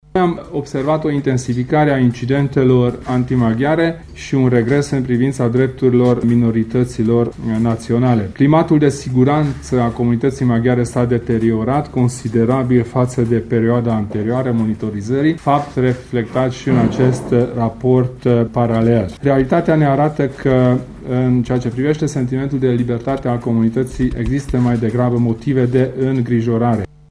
El a declarat, astăzi, la Cluj, că va reclama acest lucru la Consiliul Europei: